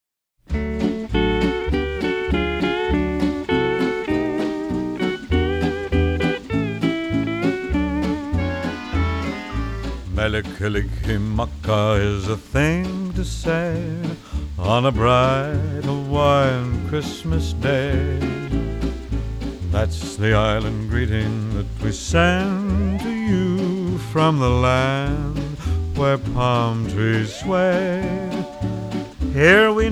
• Holiday